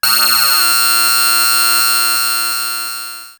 Alert17.wav